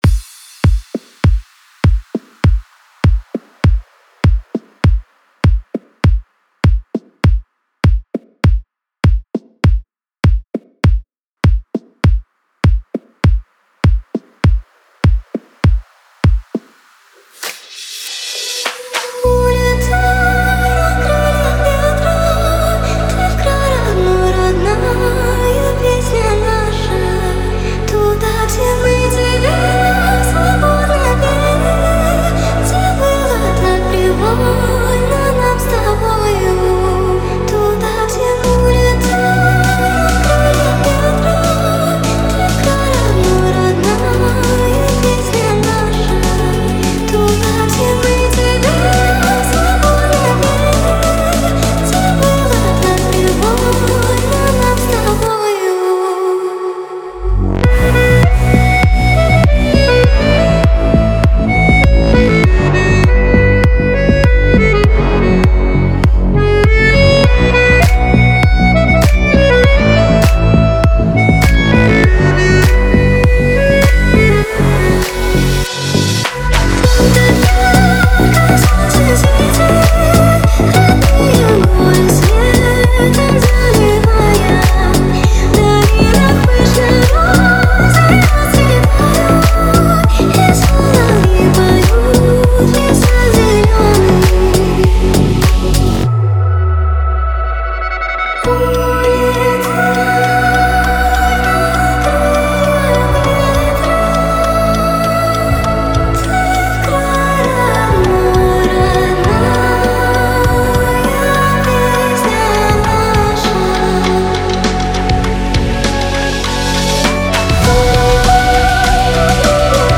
Клубная музыка
клубные ремиксы